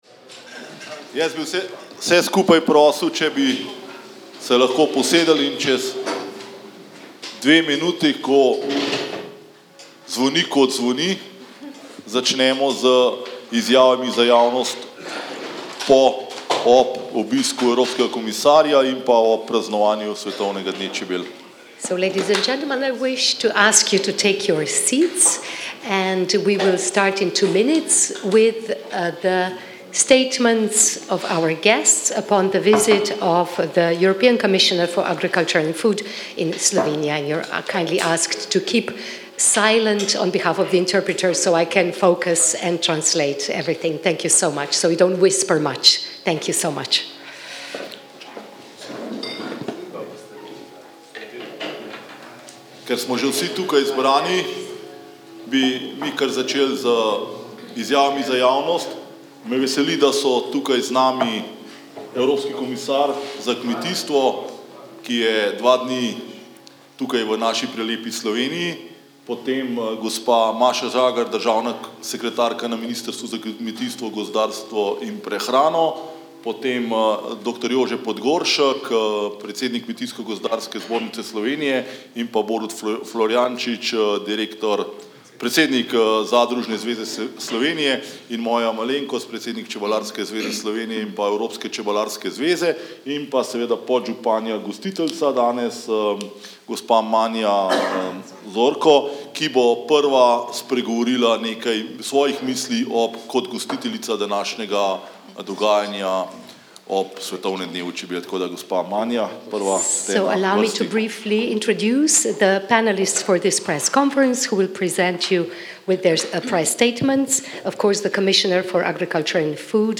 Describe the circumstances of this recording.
Meeting of beekeepers with the European Commissioner THERE